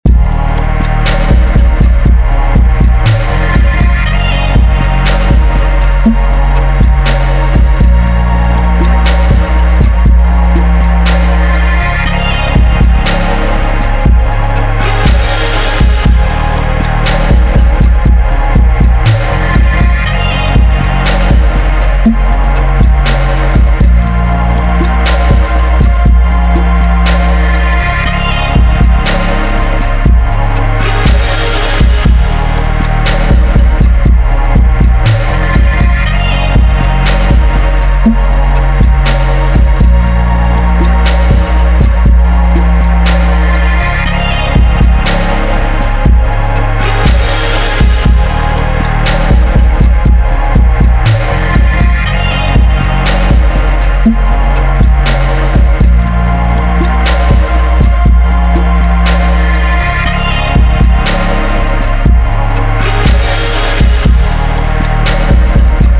I let the finished loop run at its loudest point and on the master channel I put a limiter with an automatic LUFS limit, which automatically adjusts the volume according to my input.
If at the end still not enough gain has been achieved, I softly clip the signal on the master channel before it is sent to the limiter. That mostly causes distortion, but that doesn’t have to sound bad in the chorus.